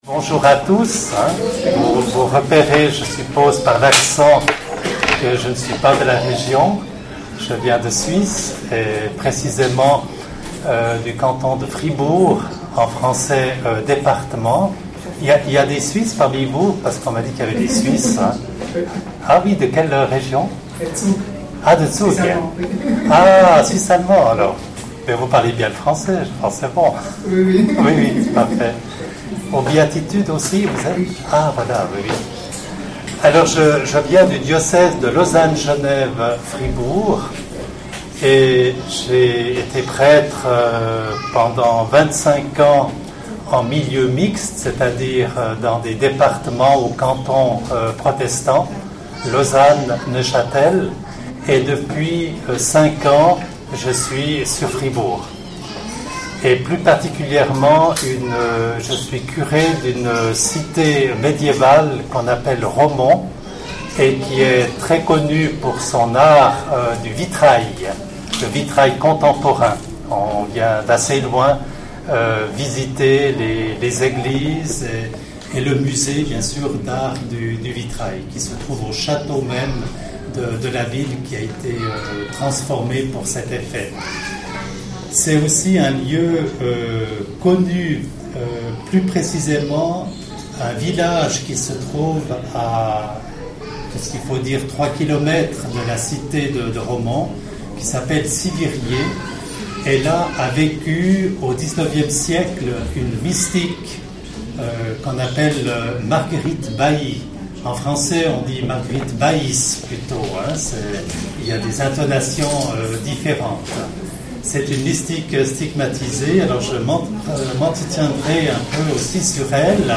Nota : Prise de son de qualit� m�diocre, d'o� le prix r�duit, mais contenu int�ressant. Atelier 4 - Enregistr� en 2016 (Session B�atitudes Lisieux)Intervenant(s